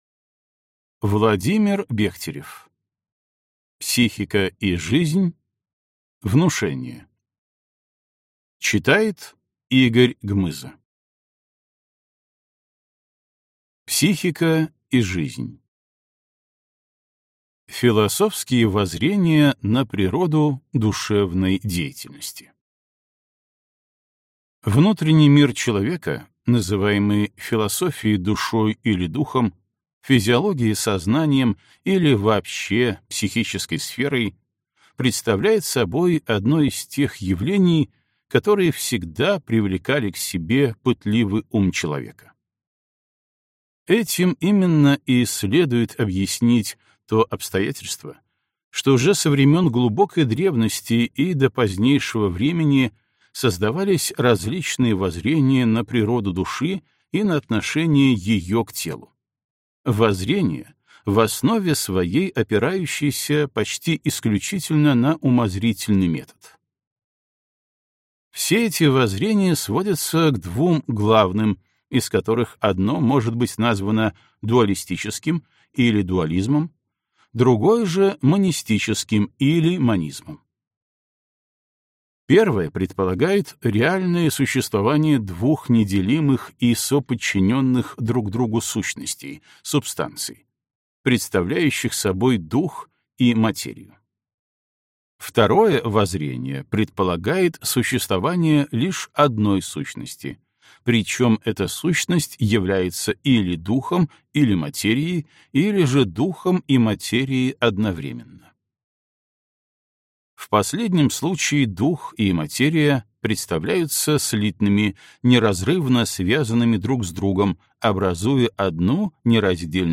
Аудиокнига Психика и жизнь. Внушение | Библиотека аудиокниг